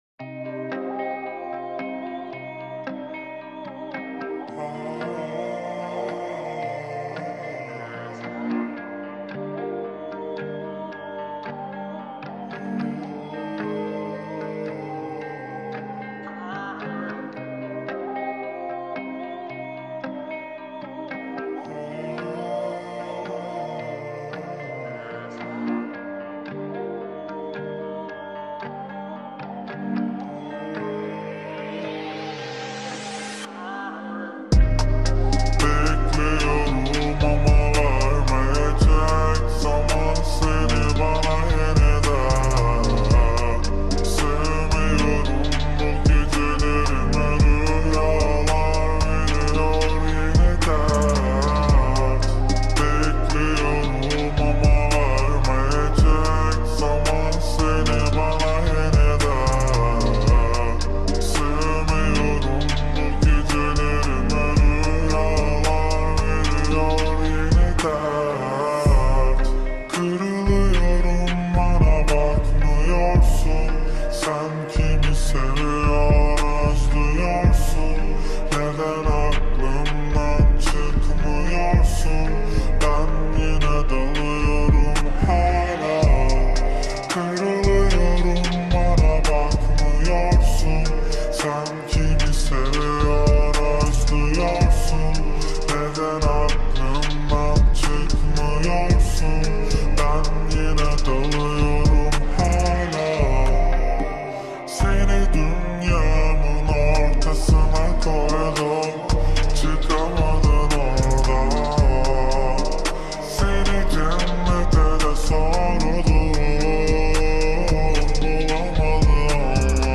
Slowed - Reverb